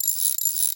missileAssembly2.ogg